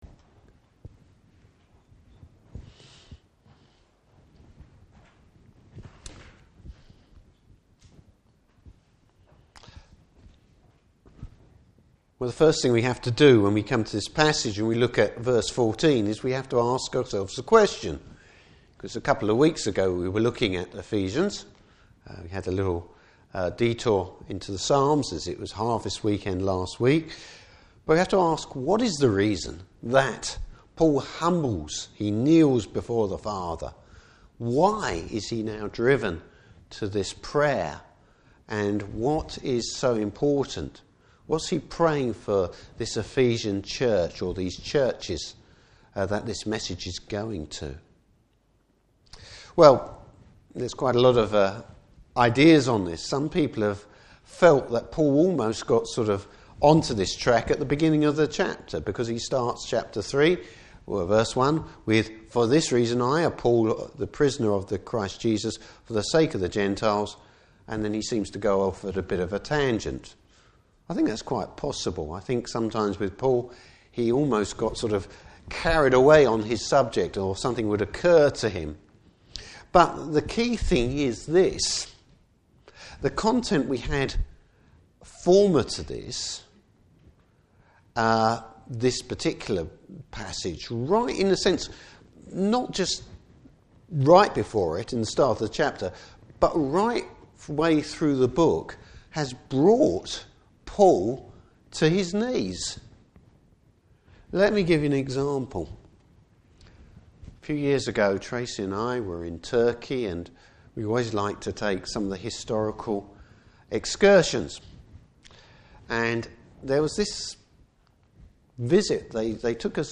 Service Type: Morning Service Bible Text: Ephesians 3:14-21.